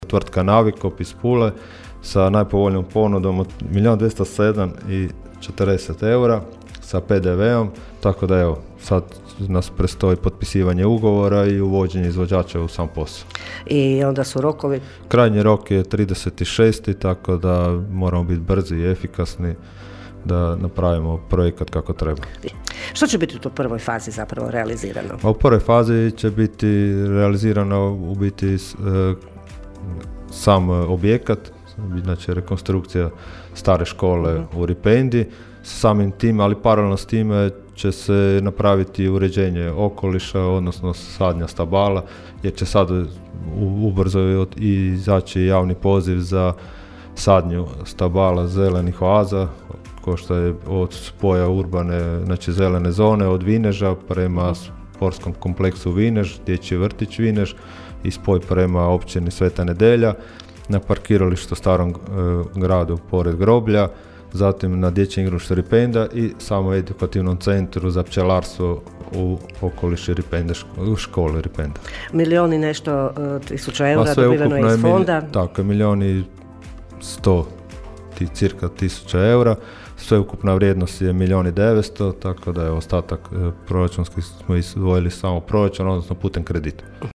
ton – Donald Blašković),  rekao je u subotnjim Gradskim minutama gradonačelnik Donald Blašković.